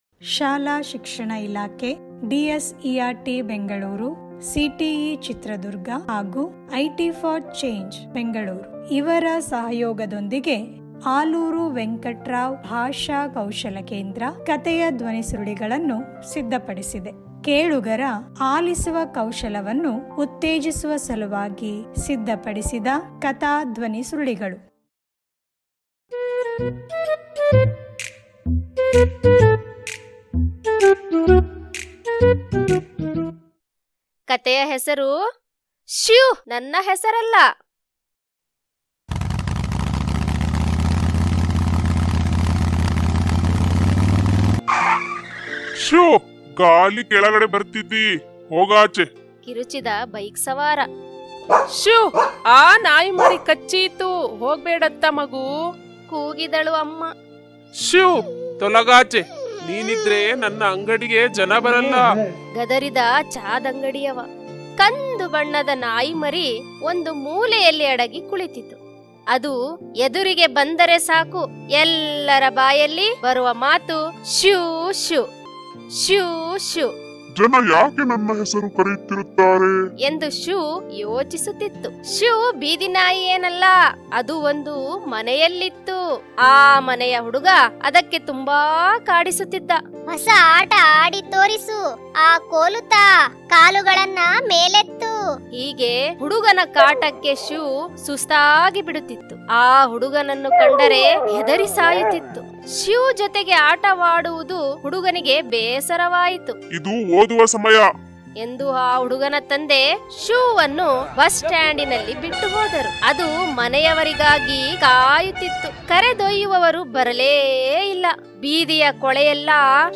ಧ್ವನಿ ಕಥೆ ಲಿಂಕ್: